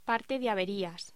Locución: Parte de averías